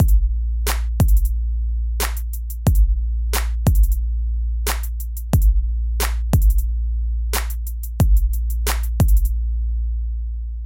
旧学校 鼓声循环14
Tag: 90 bpm Hip Hop Loops Drum Loops 1.79 MB wav Key : Unknown